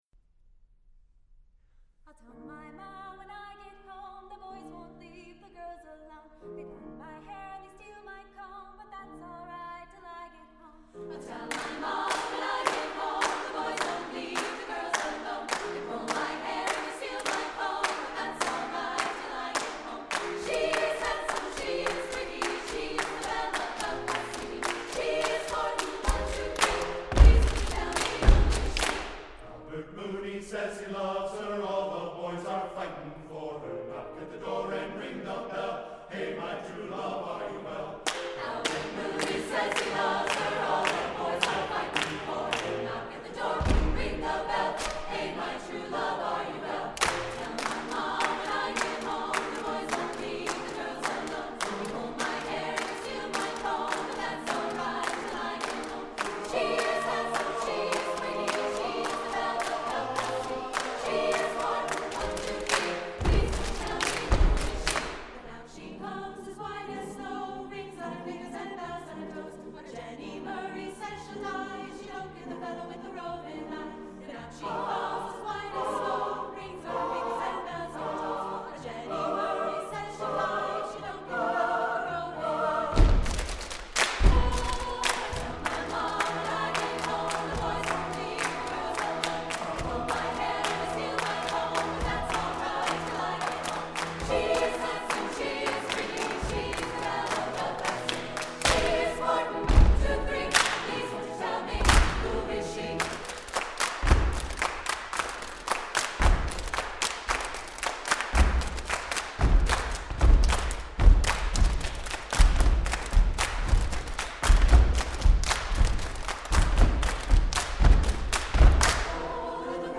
Composer: Irish Children's Son
Voicing: SATB divisi and Piano